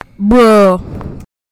bruh sound
bruh idk meme sound effect free sound royalty free Memes